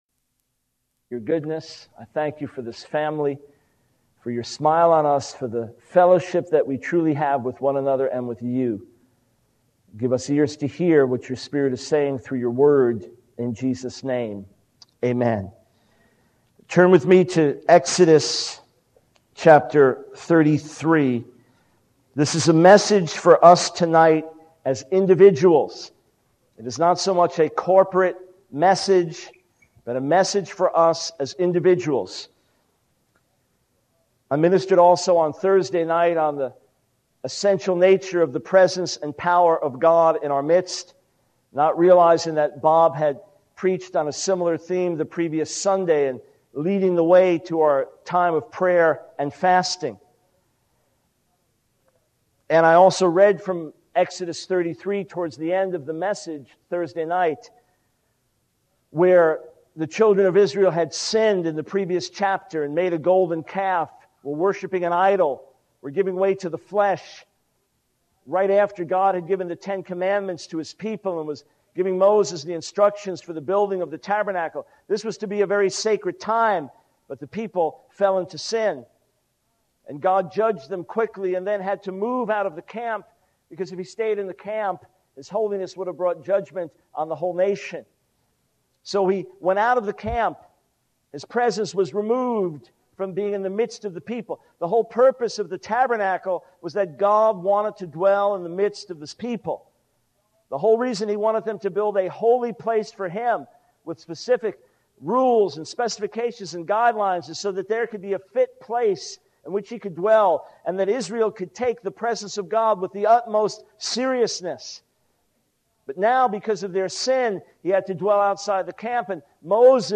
In this sermon, the speaker reflects on the book of Jeremiah and God's sense of pain and shock at the actions of His people.